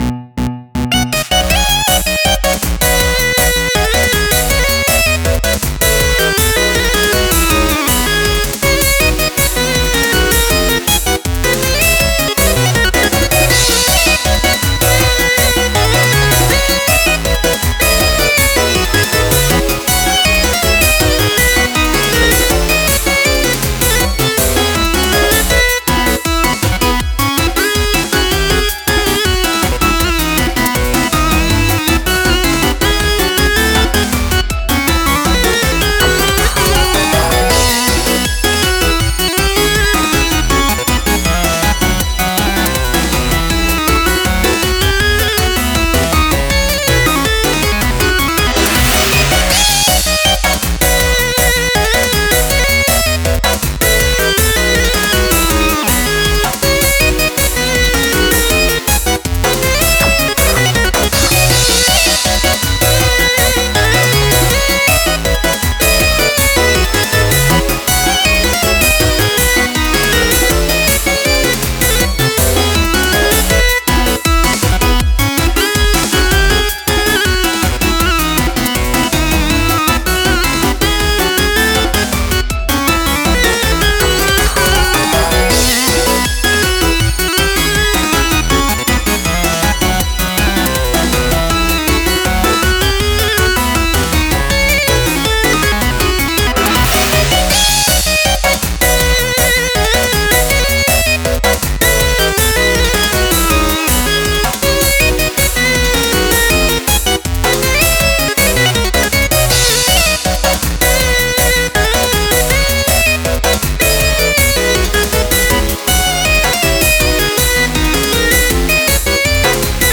明るい雰囲気の曲です。
シンセに頼りまくりです
タグ あかるい